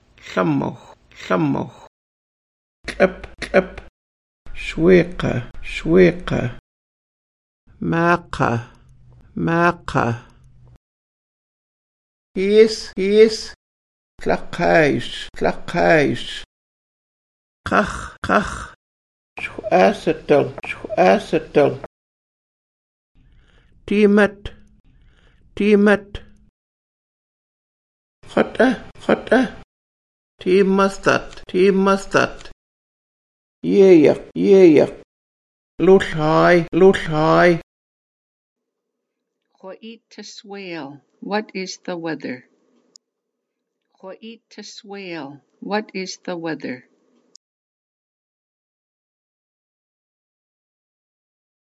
Listen to the elder
Audio Vocabulary and Phrases